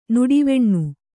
♪ nuḍiveṇṇu